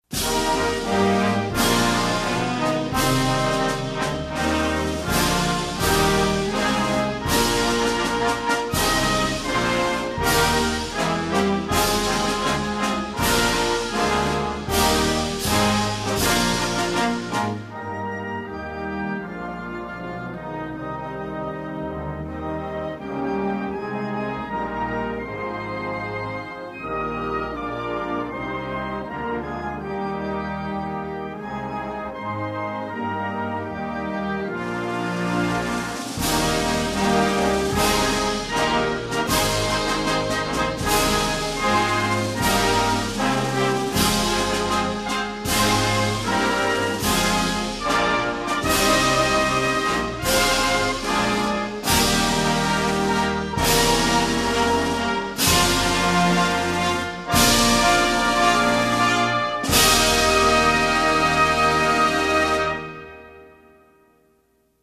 United_States_Navy_Band_-_Allah_Peliharakan_Sultan.mp3